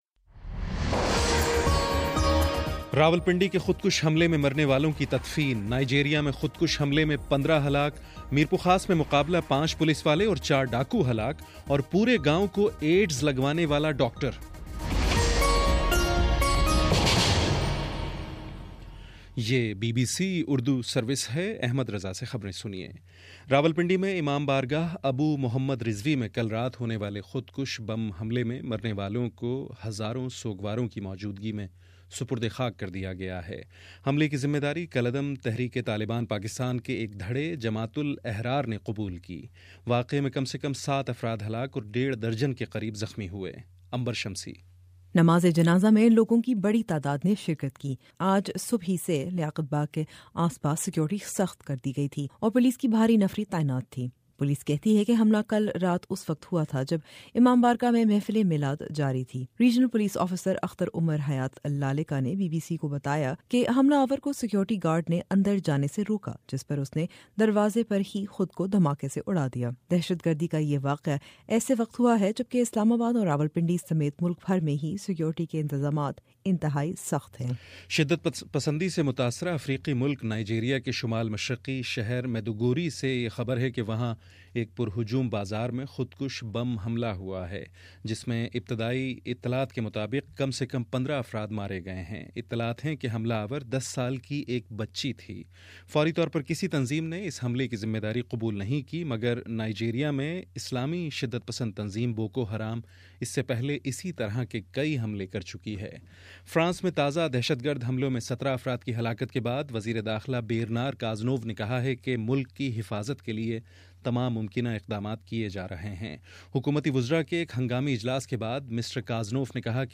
جنوری 10: شام سات بجے کا نیوز بُلیٹن